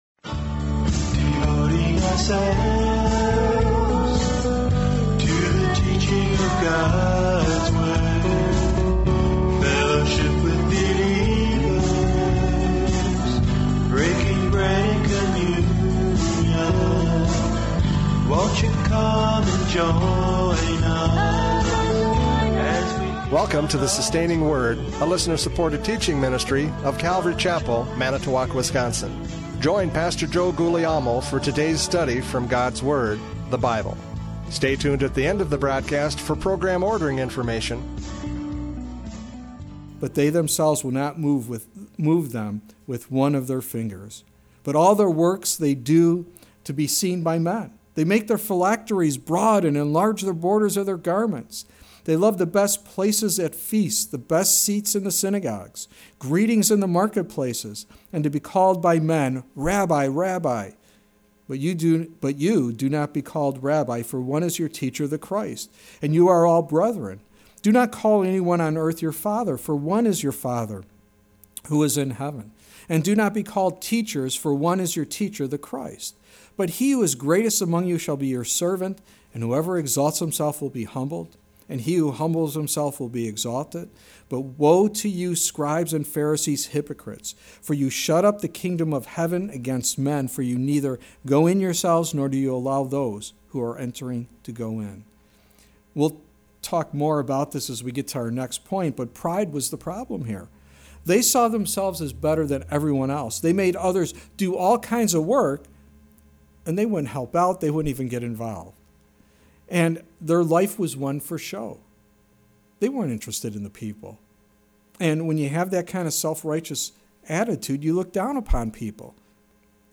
John 5:39-47 Service Type: Radio Programs « John 5:39-47 Testimony of Scripture!